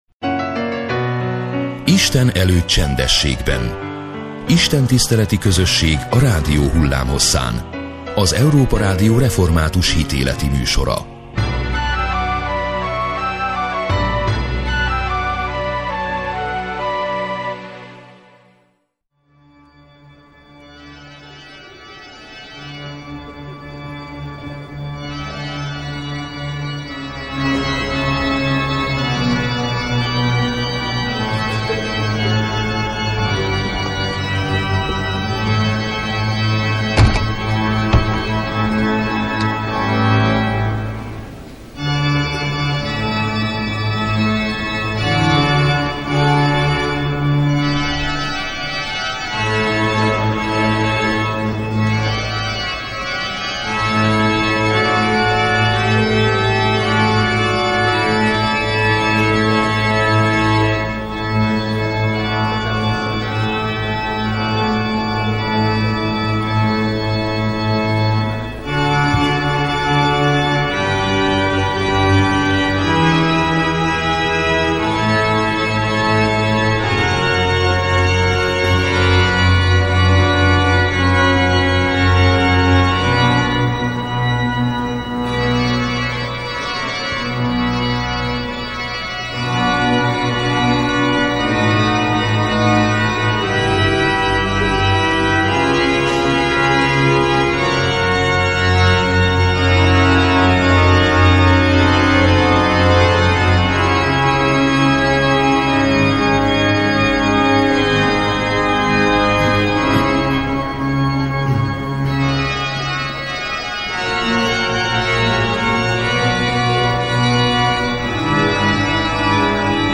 Az ünnepi istentisztelet vasárnap délelőtt úrvacsoraosztással ért véget.